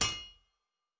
Blacksmith 01.wav